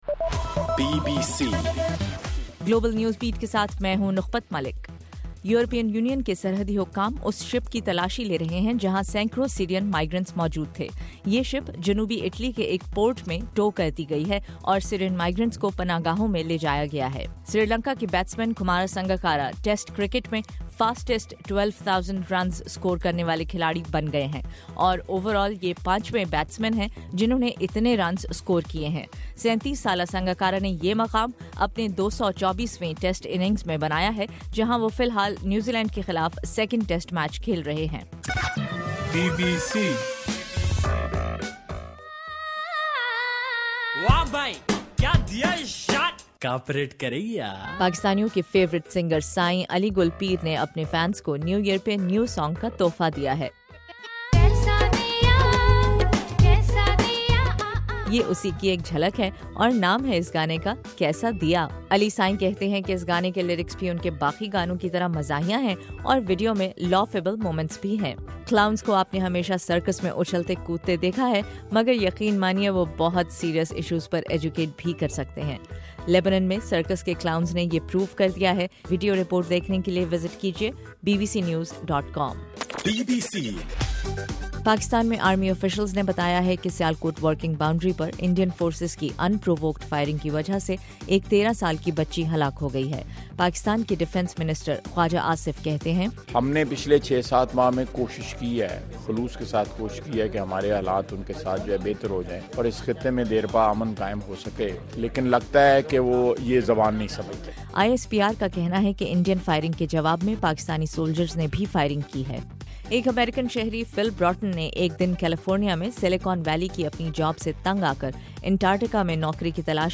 جنوری 3: رات 12 بجے کا گلوبل نیوز بیٹ بُلیٹن